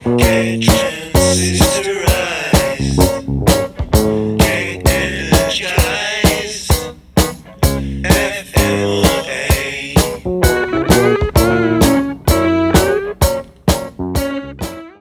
vocals / guitars
rhythm guitar
bass
drums
percussion
backing vocals